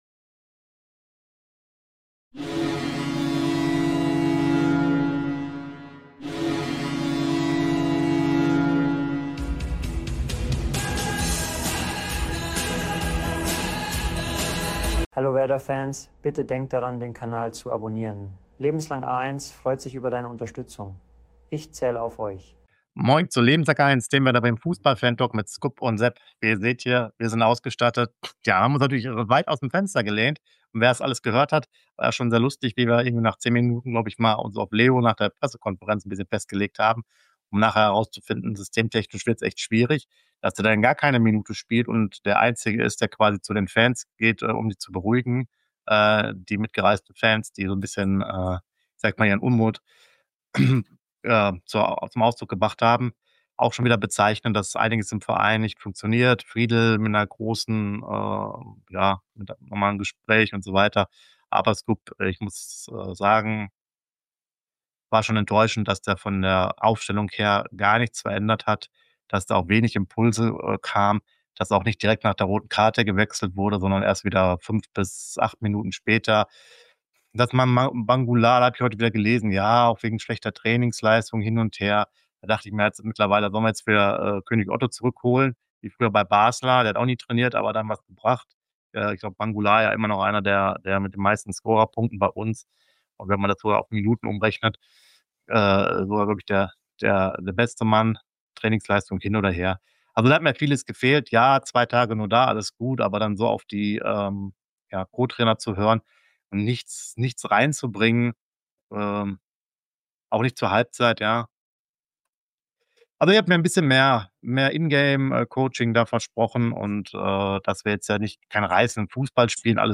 Dem Werder Bremen - Fantalk